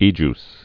(ējs, ējē-əs)